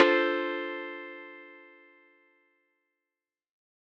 Guitar Zion 1.wav